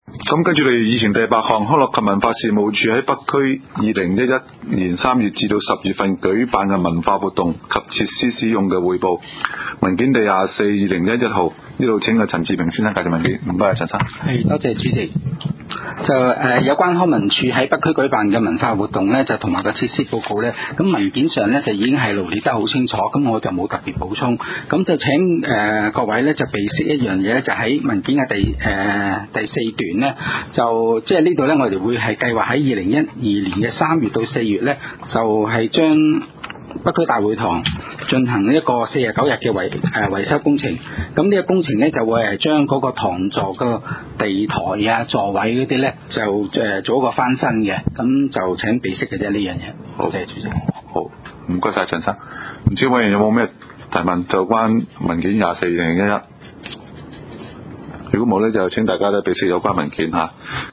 地區設施管理委員會第24次會議紀錄
地點 北區區議會會議室